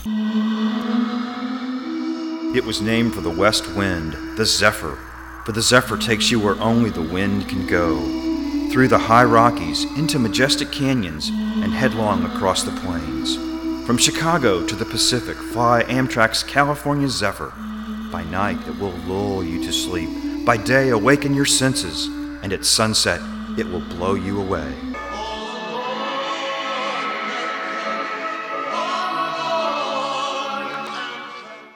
Zephyr-train-spot.mp3